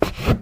MISC Wood, Foot Scrape 08.wav